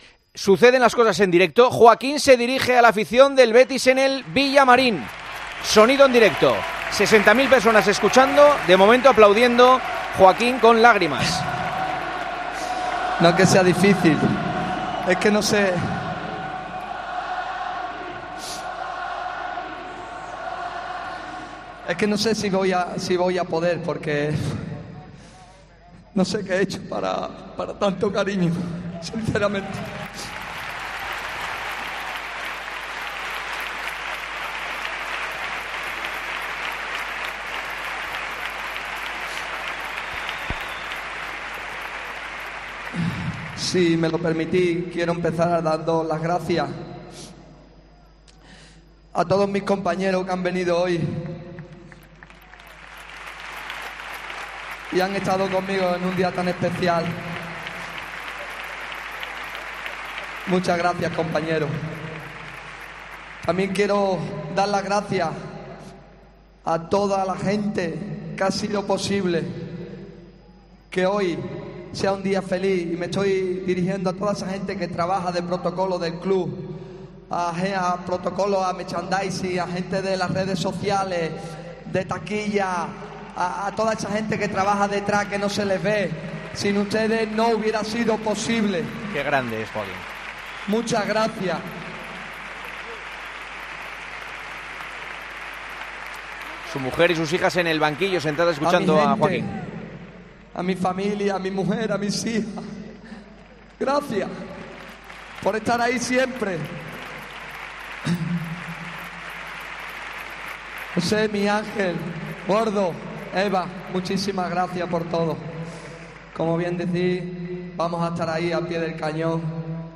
AUDIO: El futbolista recibió un gran homenaje en su último partido con la camiseta bética en el Benito Villamarín.